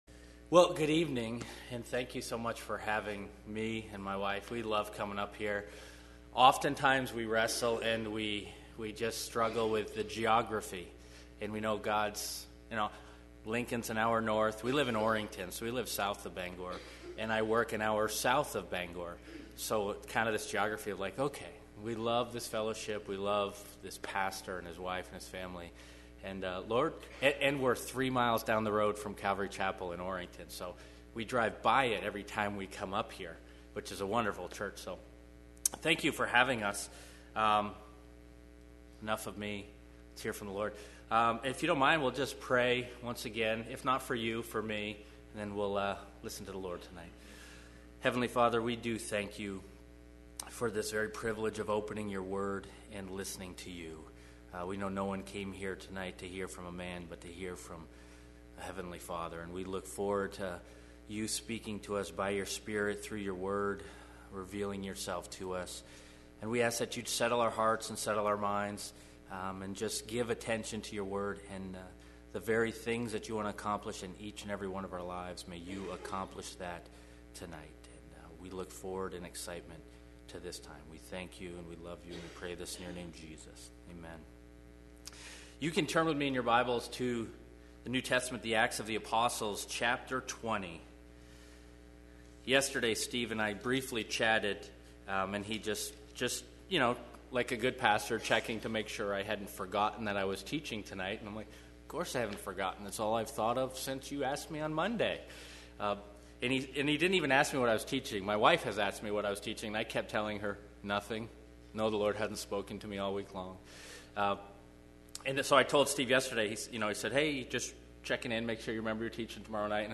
Sunday Night Teaching